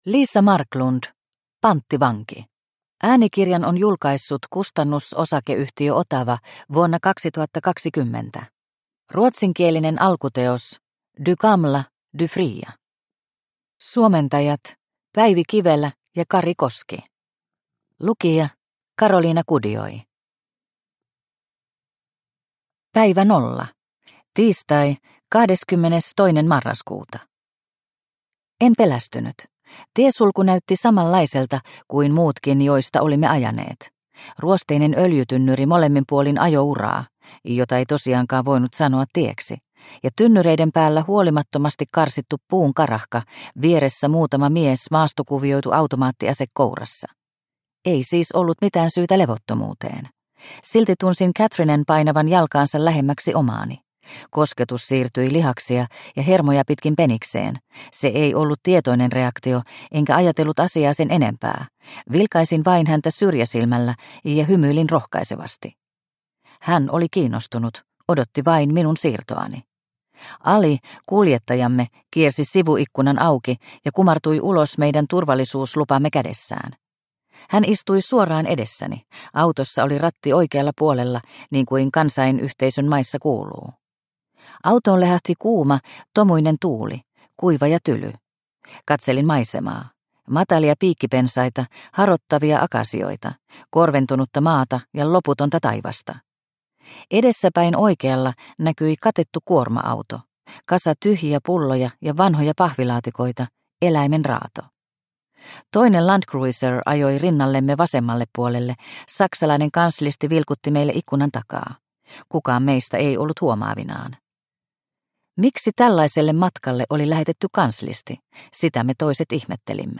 Panttivanki – Ljudbok – Laddas ner